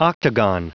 Prononciation du mot octagon en anglais (fichier audio)
Prononciation du mot : octagon